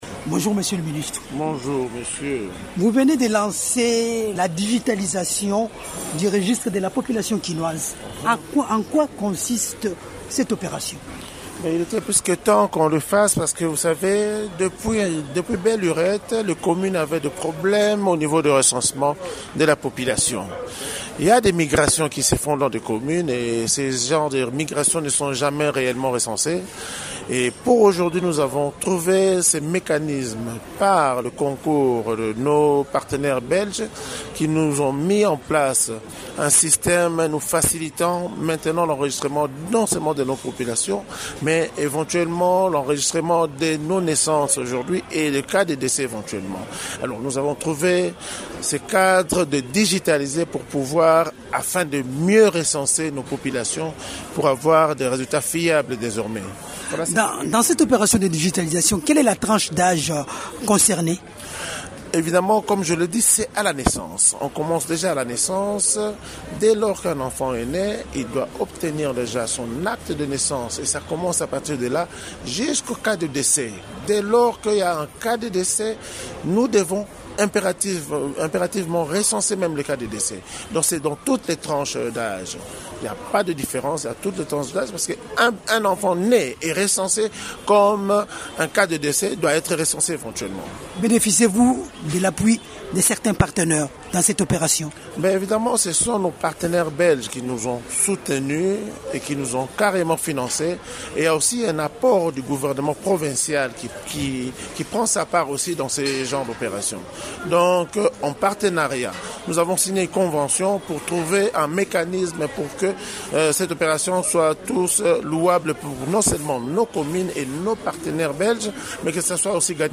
Suivez les propos de Janot Canno La Rose: